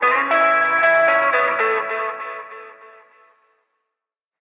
Recorded at Beware of Dog Studios - Chicago, IL 2011-2012